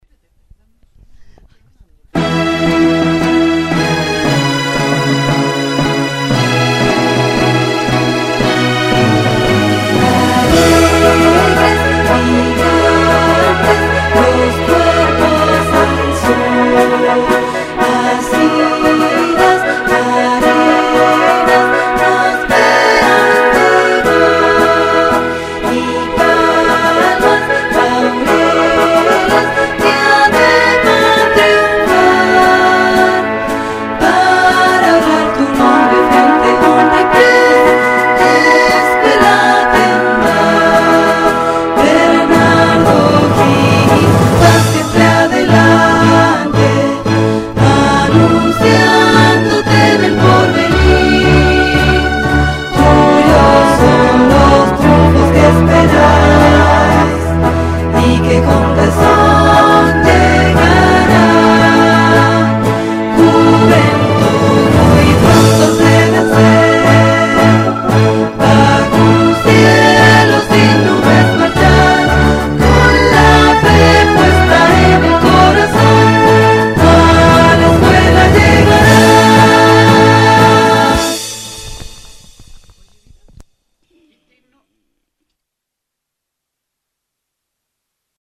Himno con coro
himno-esc-d-17-con-coro.mp3